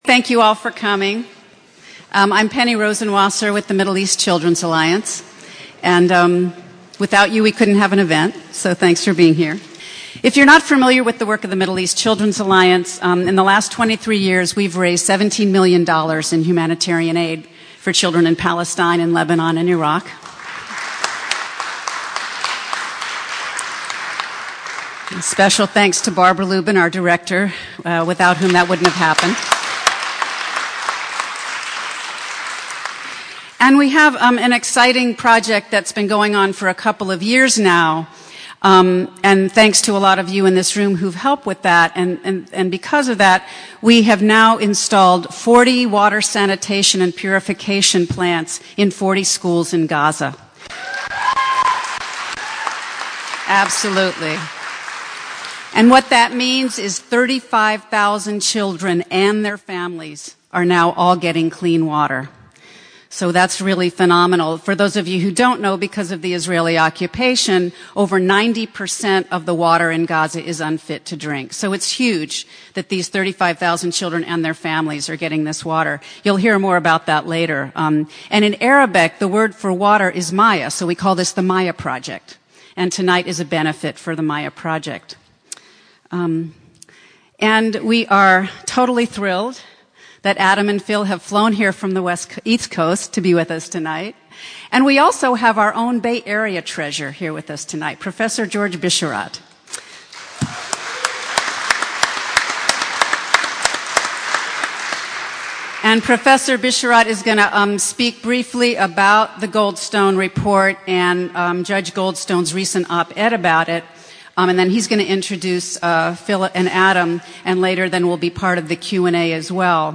at an event organized by Middle East Children’s Alliance (MECA) to launch of their new book